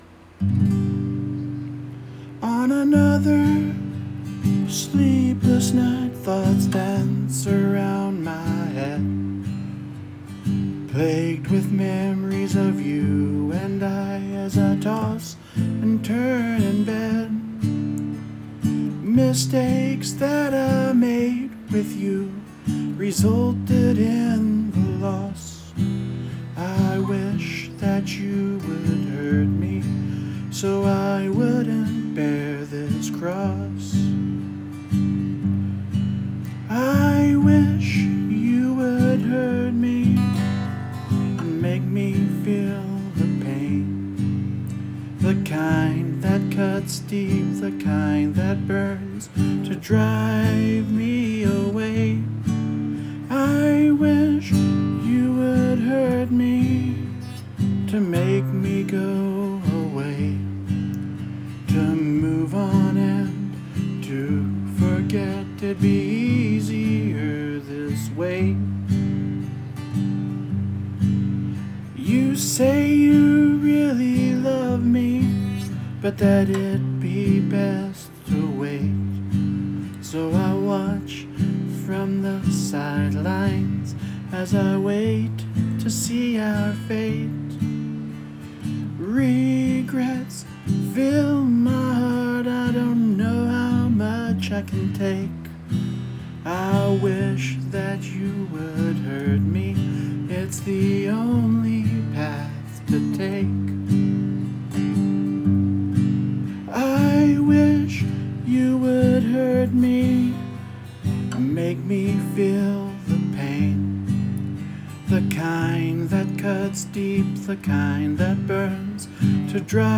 It’s pretty imperfect, first time I ever played it.